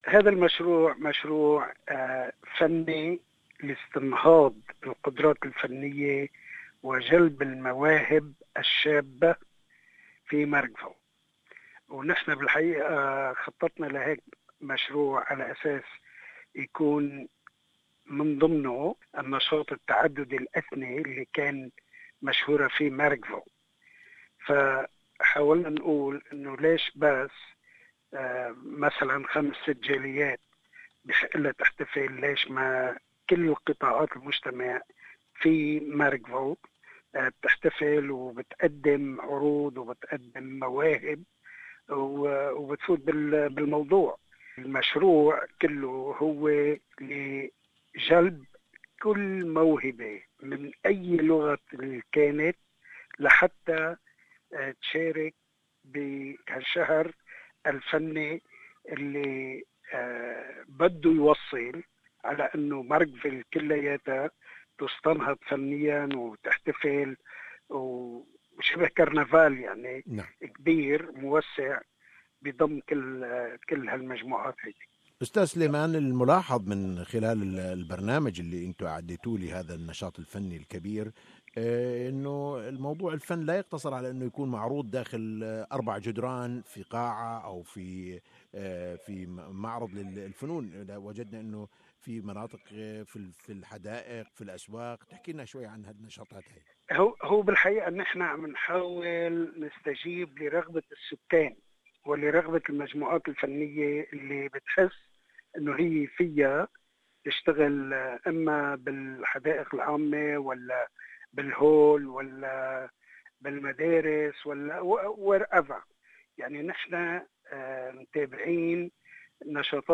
Mayor of Marrickville Sam Iskandar speaks about this event .